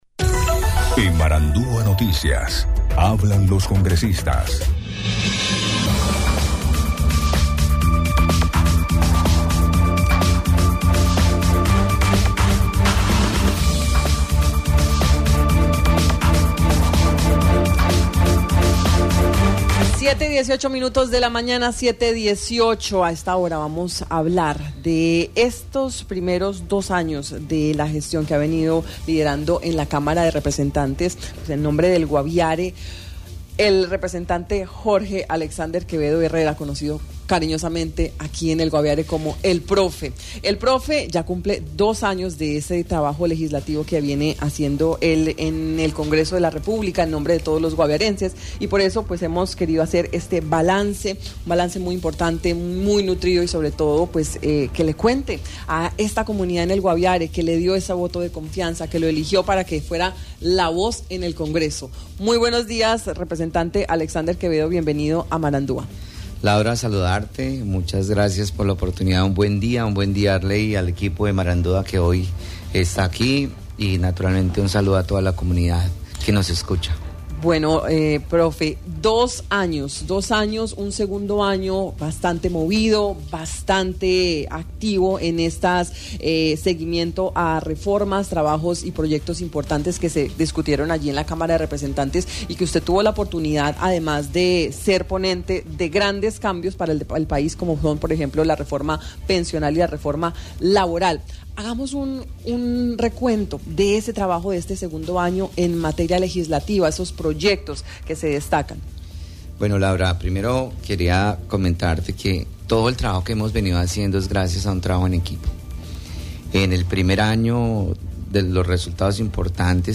El Representante a la Cámara por el Guaviare, Alexander Quevedo, presentó en Marandua Noticias un segundo informe legislativo sobre las leyes en favor del Guaviare que ha podido presentar en estas dos vigencias de actividades en el congreso de la República.